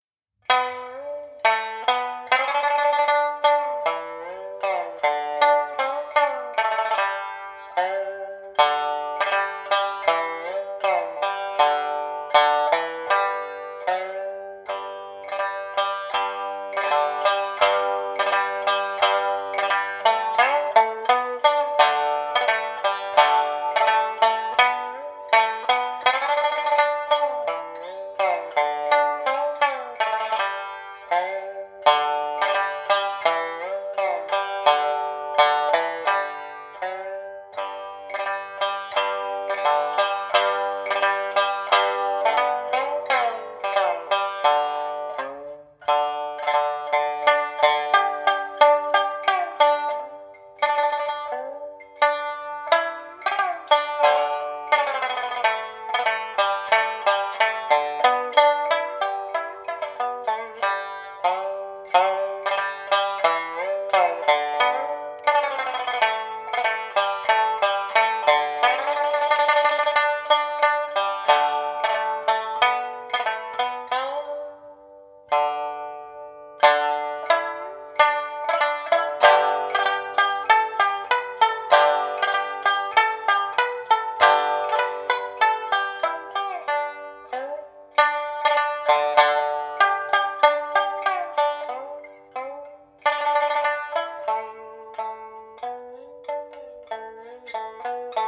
尺八
琵琶
三弦，古老的民族弹拨乐器，声音浑厚、刚柔兼具，广泛的出现在说唱音乐、戏曲音乐中，但独奏、主奏形式比较少见